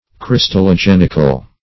Meaning of crystallogenical. crystallogenical synonyms, pronunciation, spelling and more from Free Dictionary.
Crystallogenical \Crys`tal*lo*gen"ic*al\ (-?-kal), a.